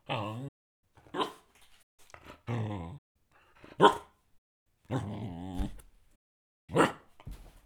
dog-dataset
dogs_0022.wav